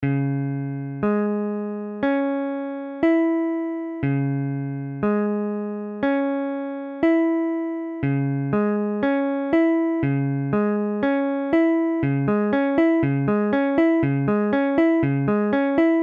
Tablature Dbm.abcDbm : accord de Ré bémol mineur
Mesure : 4/4
Tempo : 1/4=60
A la guitare, on réalise souvent les accords en plaçant la tierce à l'octave.
Ré bémol mineur barré IV (la bémol case 4 ré bémol case 4 la bémol case 6 doigt 3 ré bémol case6 doigt 4 mi case 5 doigt 2 la bémol case 4)